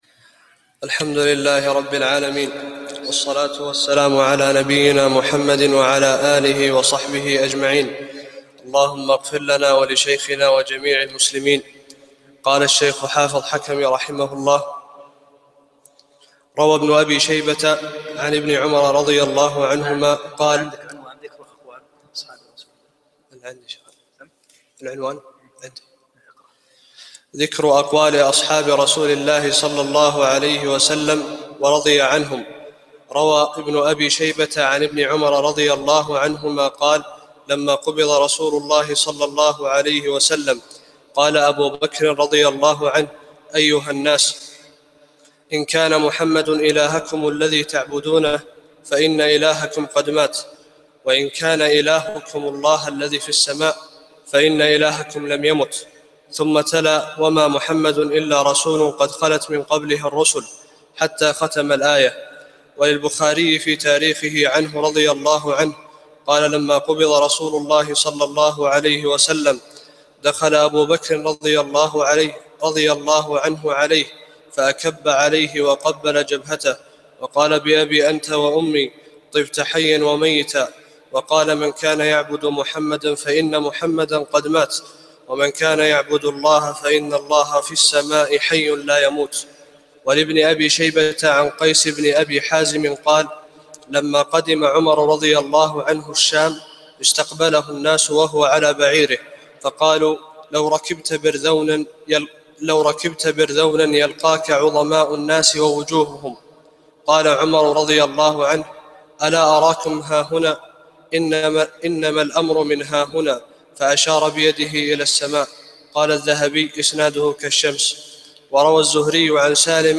19 - الدرس التاسع عشر